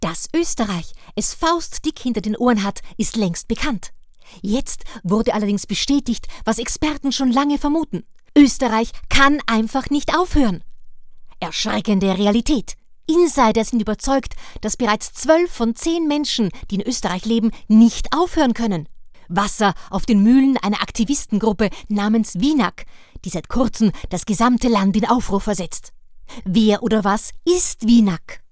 Synchronstimme, bekannt aus TV-und Radio, Moderatorin, Off-Sprecherin,Schauspielerin
Sprechprobe: Sonstiges (Muttersprache):